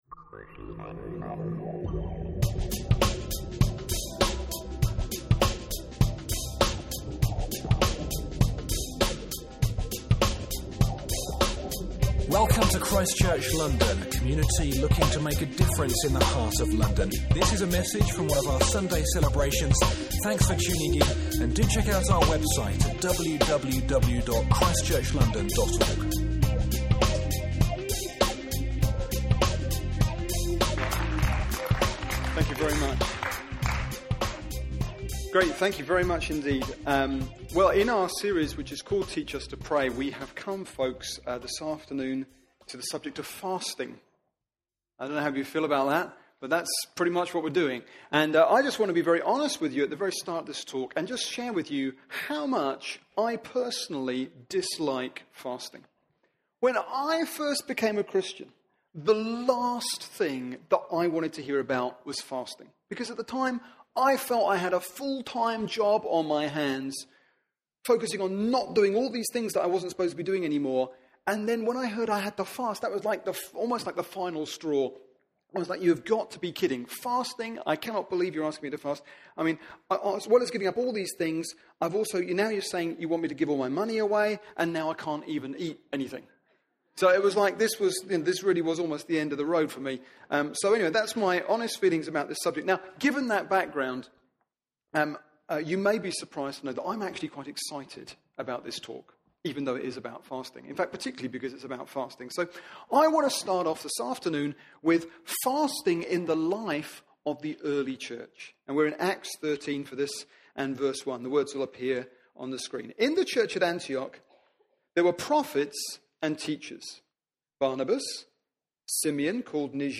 Acts 13: 1-4, Matt: 9 14-17 – Preaching from ChristChurch London’s Sunday Service.